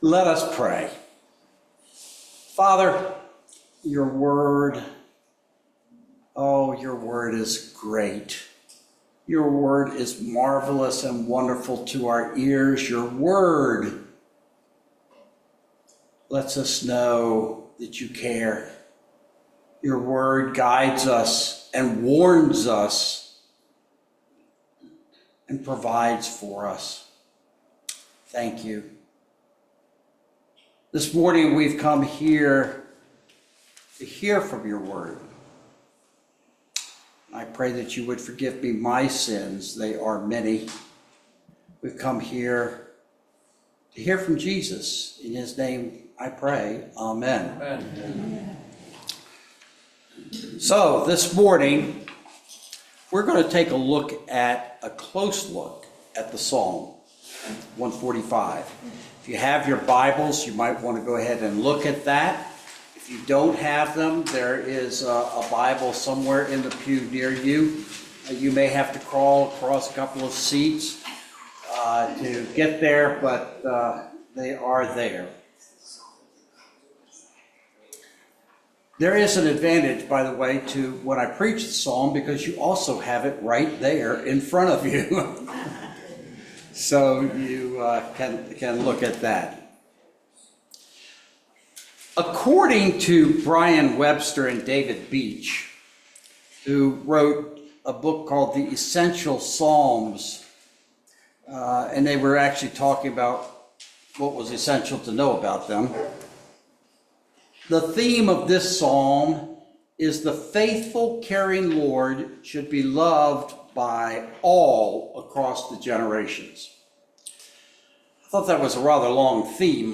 A message from the series "Sunday Series."